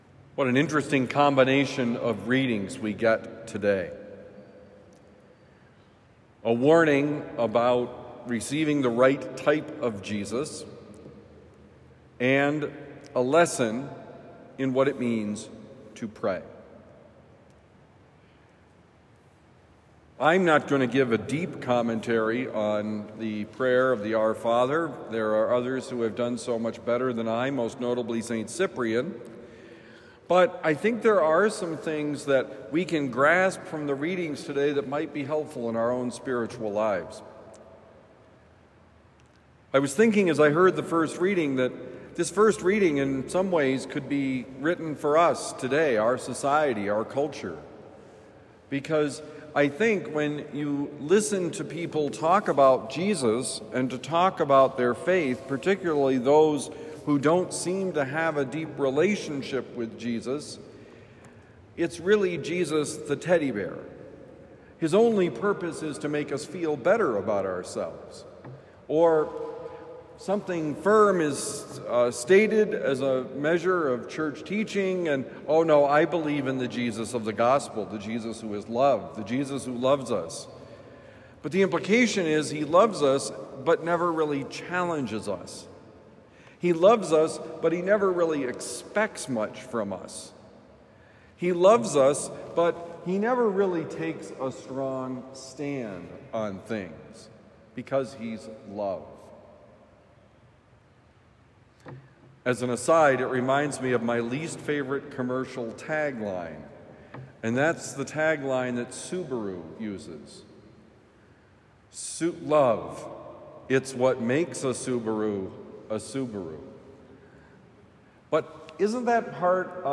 Forgive to be forgiven: Homily for Thursday, June 19, 2025